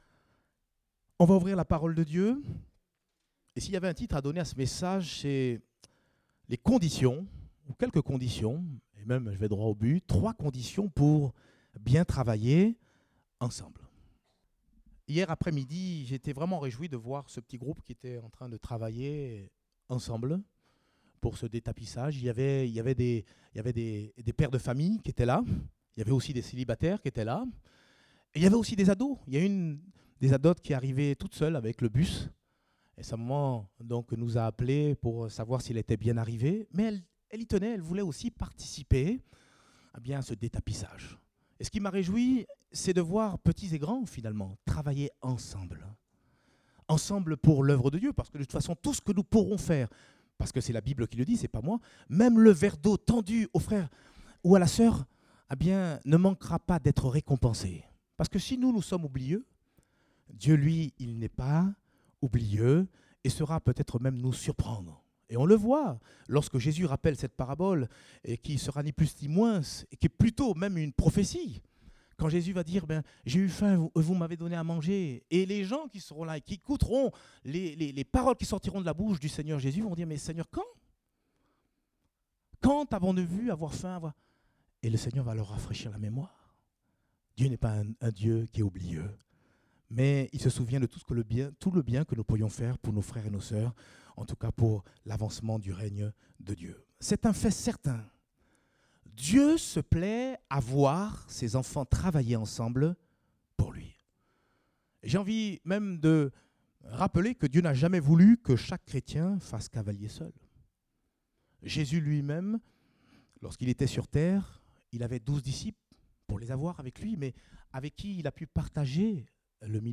Date : 25 mars 2018 (Culte Dominical)